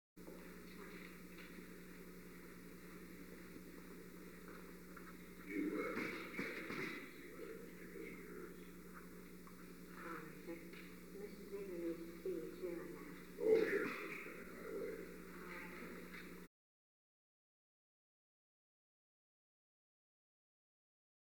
Conversation: 865-015
Recording Device: Oval Office
The Oval Office taping system captured this recording, which is known as Conversation 865-015 of the White House Tapes.
The President met with an unknown woman.